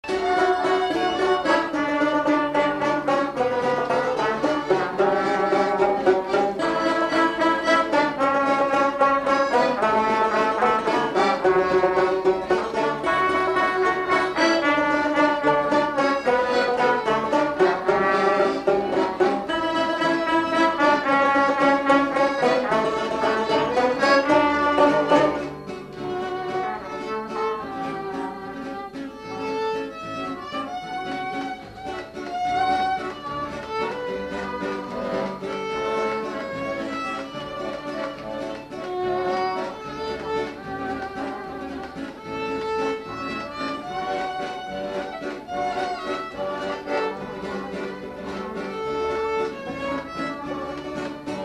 Résumé Instrumental
Catégorie Pièce musicale inédite